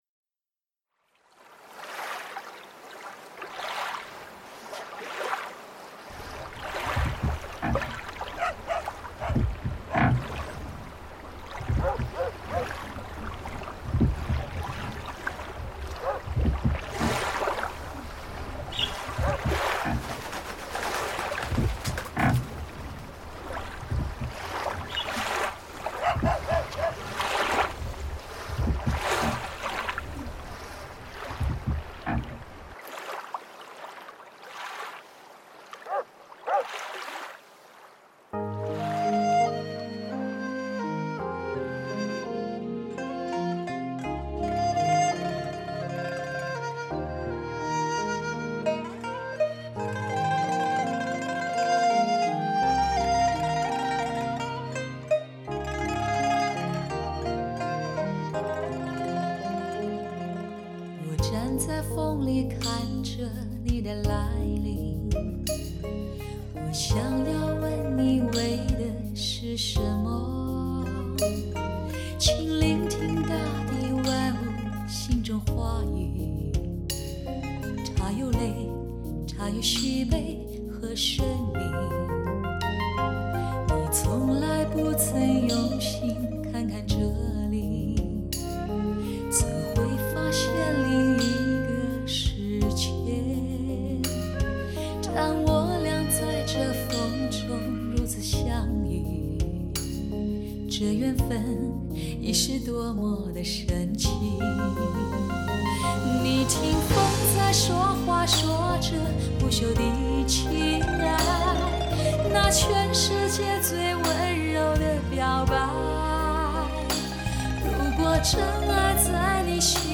神韵音色，再度重现，萦绕于耳畔的神话般美妙单色，令你惊叹不已。
具备高保真的声音品质和最自然传神的聆听效果。